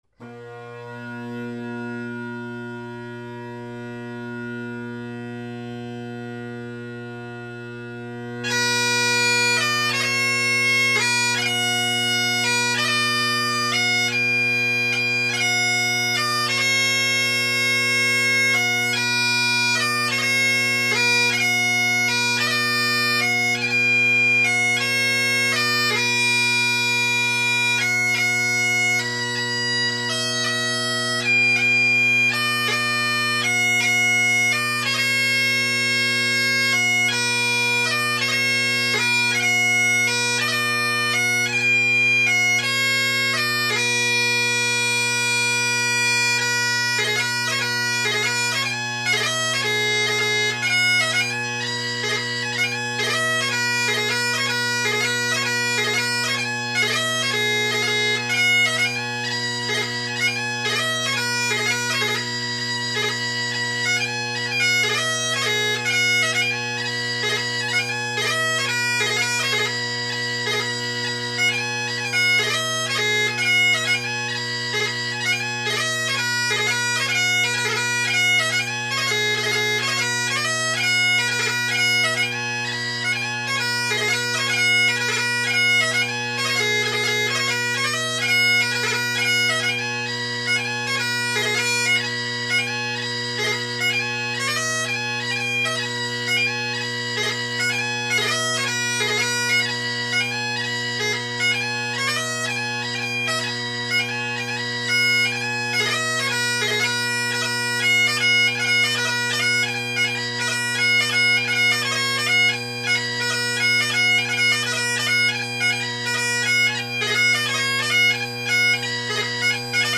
Great Highland Bagpipe Solo
It’s a very bass dominant sound at the moment.
Echo Lake, Highland Harry, Bessie McIntyre, Captain Lachlan MacPhail of Tiree – facing mic